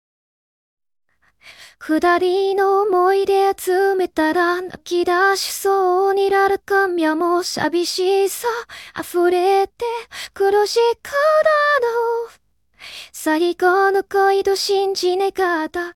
唱歌表现